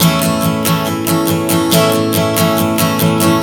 Strum 140 A 01.wav